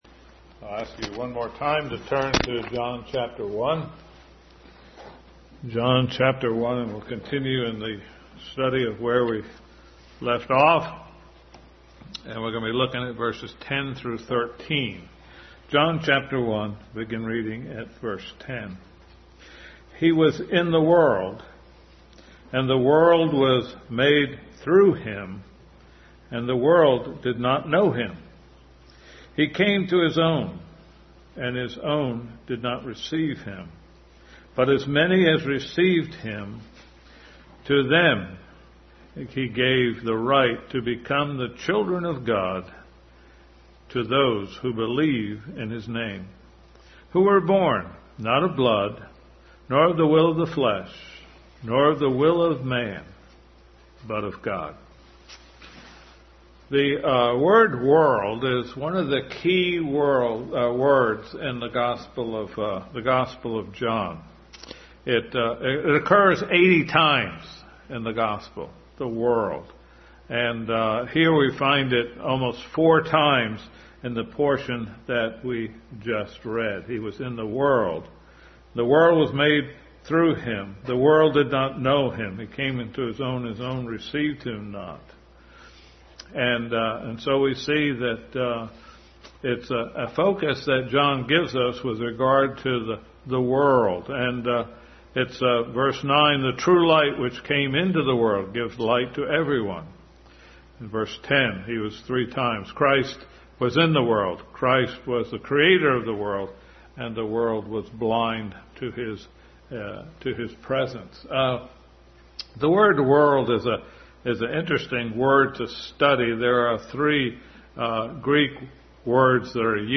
John 1:1-=13 Passage: John 1:10-13, 1 John 2:15-17, John 12:31, 14:30, 16:16, Ephesians 2:2, 1 Timothy 2:1-6, 2 Corinthians 3:18-4:4 Service Type: Family Bible Hour Family Bible hour message.